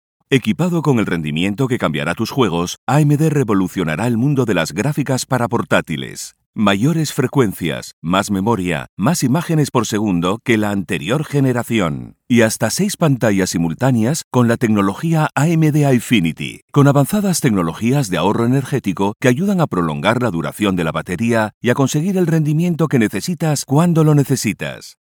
Corporate 1